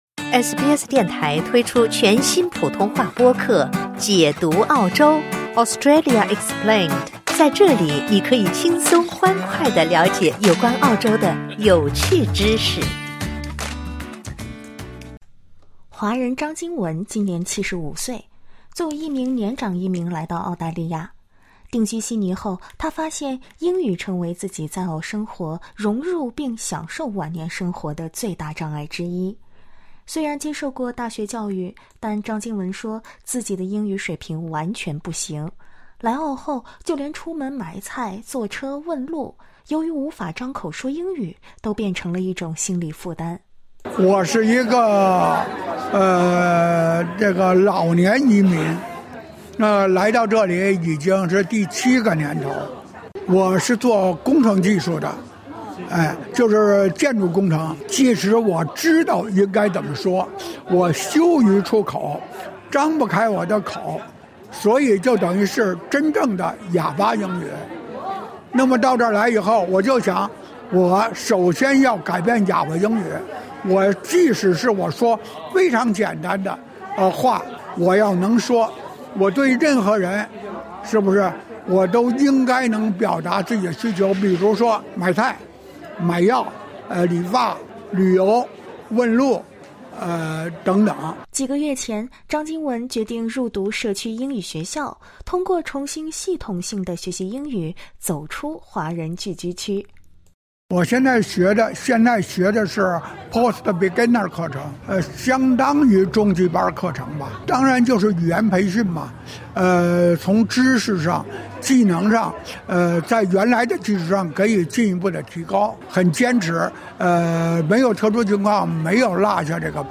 （点击上方收听音频报道）